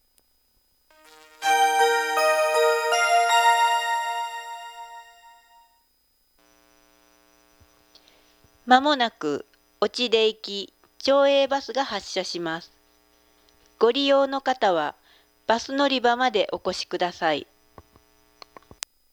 また、スピーカーはRAMSAです。
メロディが鳴ってから放送が入るまで3秒程度間隔があり、アナウンサーもJR社員等を起用して喋らせている様に聞き取れます。